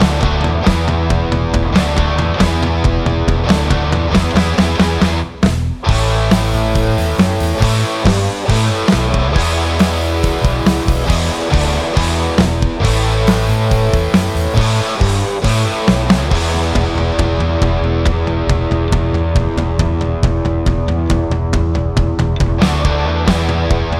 no Backing Vocals Rock 3:45 Buy £1.50